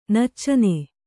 ♪ naccane